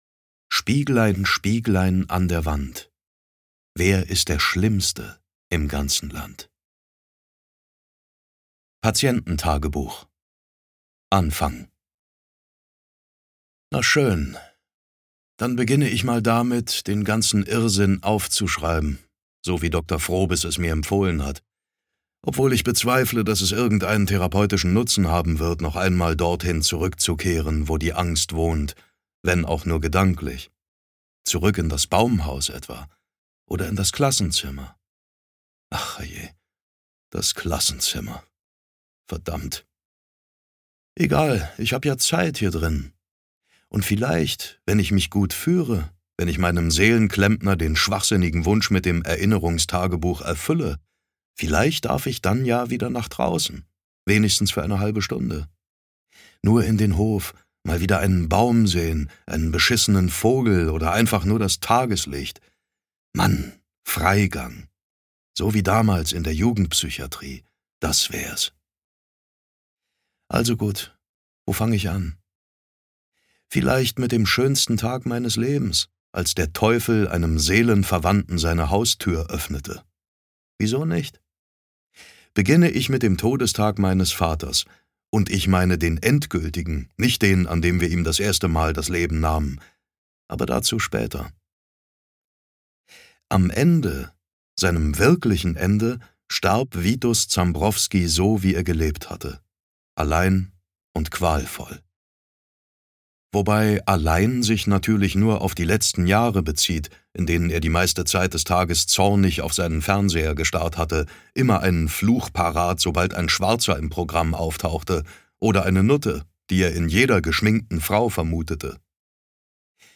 Das Hörbuch Die Blutschule von Max Rhode ist ein gnadenloser Horror-Thriller, der dich von der ersten Minute an in einen Albtraum zieht.
Gekürzt Autorisierte, d.h. von Autor:innen und / oder Verlagen freigegebene, bearbeitete Fassung.
Die Blutschule Gelesen von: David Nathan